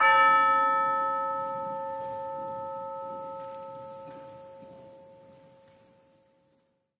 注：有现场观众在场，因此背景中有运动声、咳嗽声等。 近距离话筒是Josephson C720的前囊，通过API 3124+前置放大器，同时用放置在教堂周围的各种话筒捕捉源头的更多环境参数。
标签： 报时 管弦乐 打击乐 振铃 管状
声道立体声